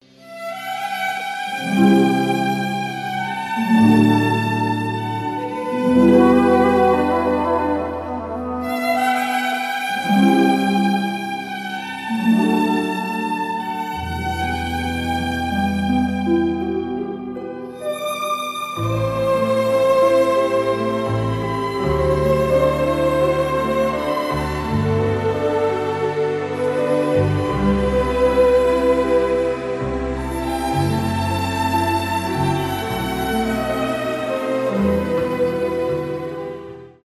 без слов , инструментальные
красивая мелодия